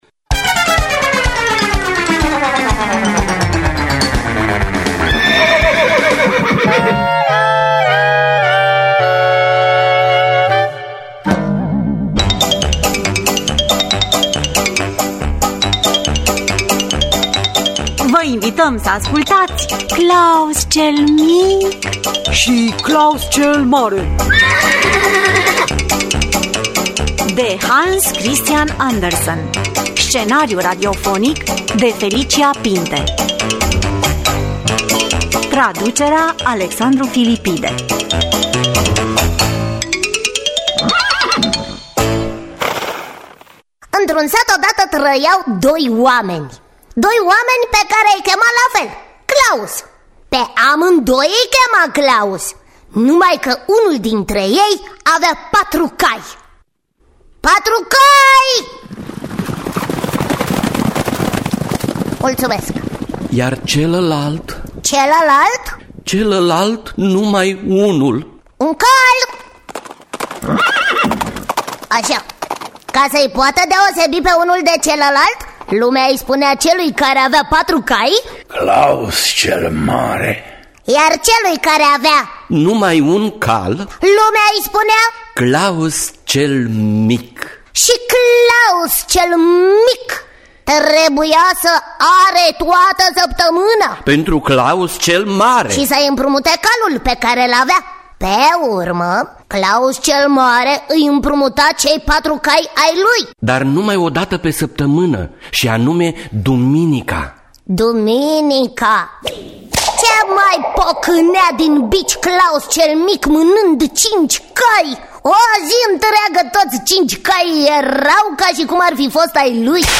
Klaus cel mic şi Klaus cel mare de Hans Christian Andersen – Teatru Radiofonic Online
Dramatizarea şi adaptarea radiofonică